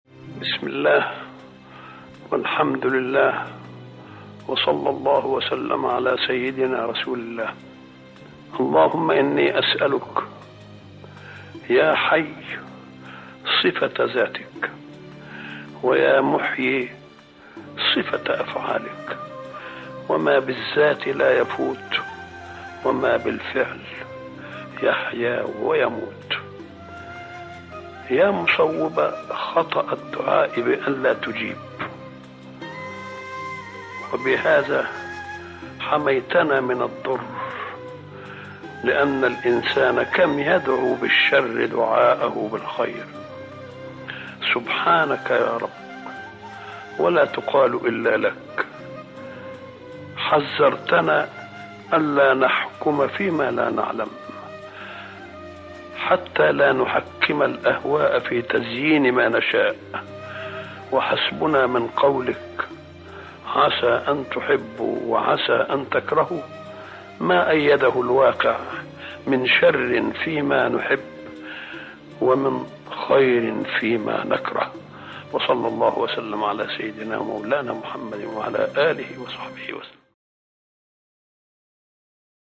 دعاء خاشع يعبر عن التسليم الكامل لقضاء الله وقدره، مع الاعتراف بحكمته المطلقة في تقدير الخير والشر. النص يوجه القلب إلى الأنس بالله والاستعانة به وحده، مختتماً بالصلاة على النبي محمد.